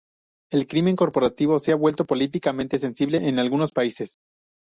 Pronounced as (IPA) /ˈbwelto/